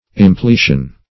Search Result for " impletion" : The Collaborative International Dictionary of English v.0.48: Impletion \Im*ple"tion\, n. [L. impletio.